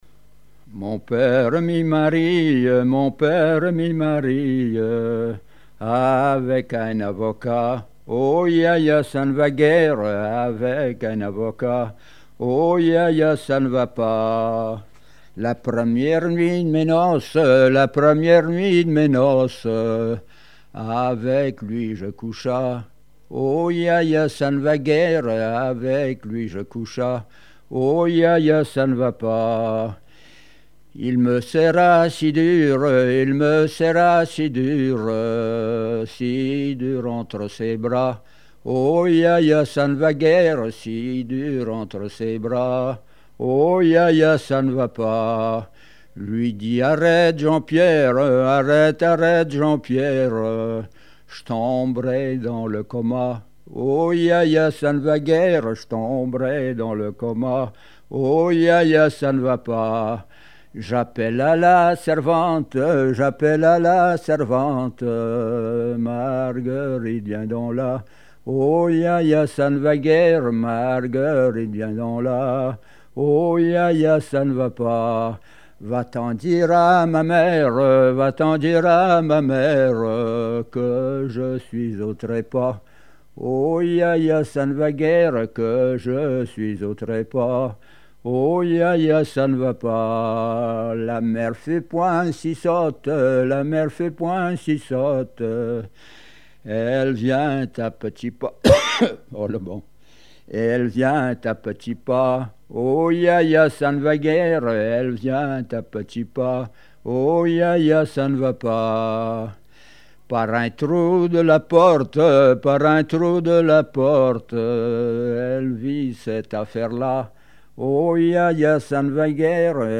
Mémoires et Patrimoines vivants - RaddO est une base de données d'archives iconographiques et sonores.
Répertoire de chansons traditionnelles et populaires
Pièce musicale inédite